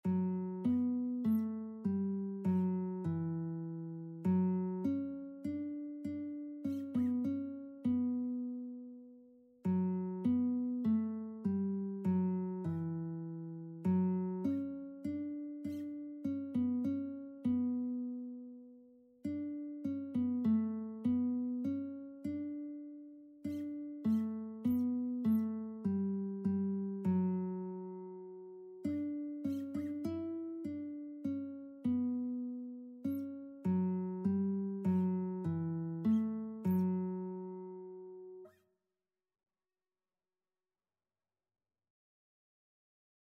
Christian
4/4 (View more 4/4 Music)
Classical (View more Classical Lead Sheets Music)